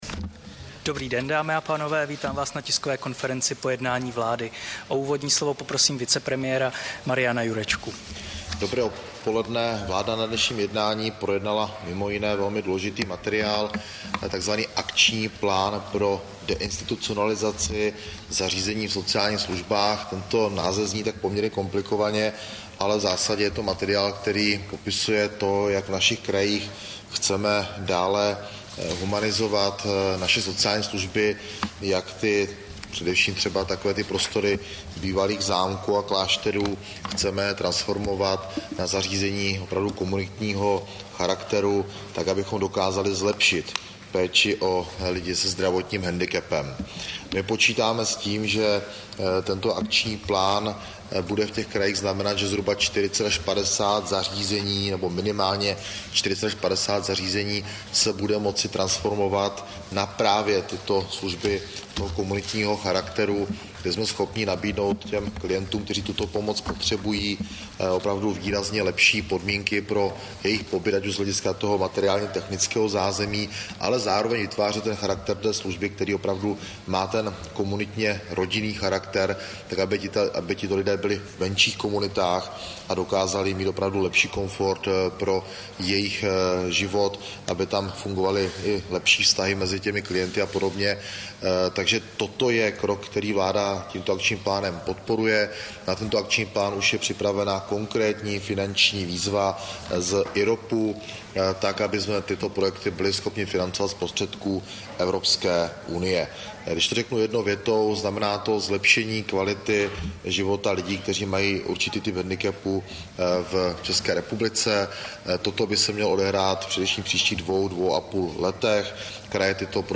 Tisková konference po jednání vlády, 18. října 2023